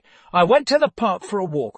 tortoise-tts - (A fork of) a multi-voice TTS system trained with an emphasis on quality
angry.mp3